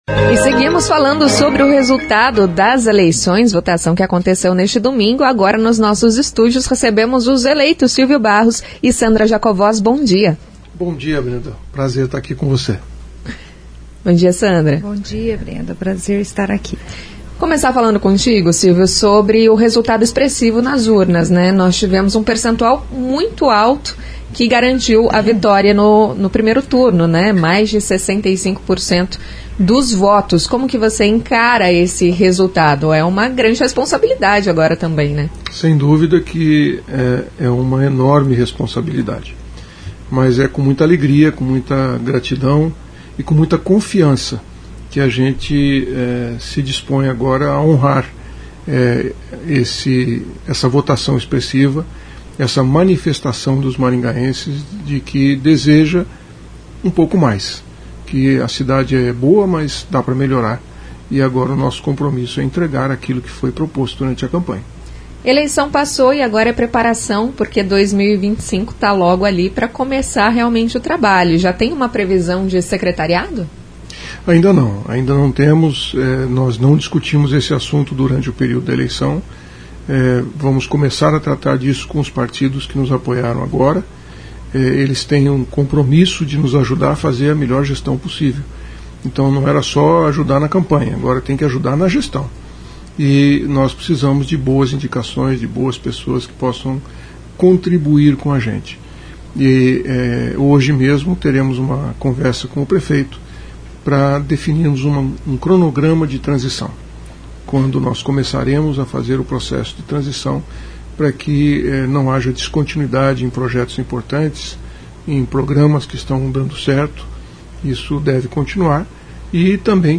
Em entrevista à CBN Maringá, o prefeito eleito Silvio Barros e a vice Sandra Jacovós agradecem a confiança do eleitor.